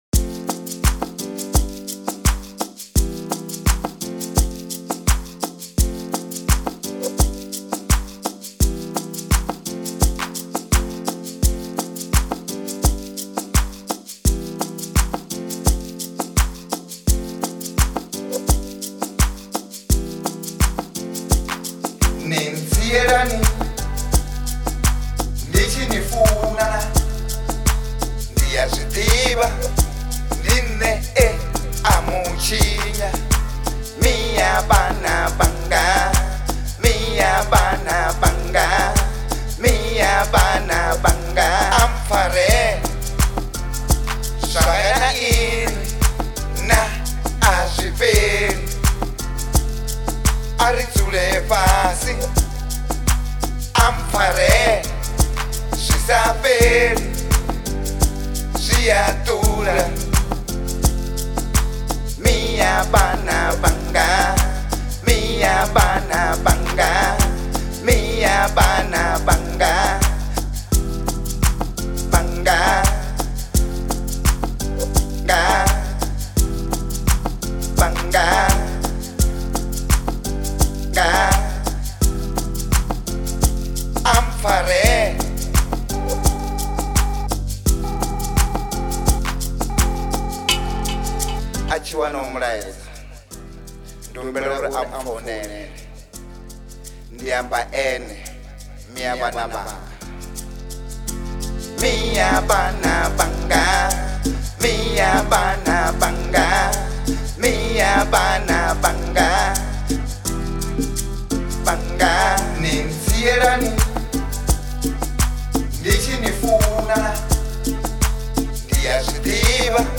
03:17 Genre : Venrap Size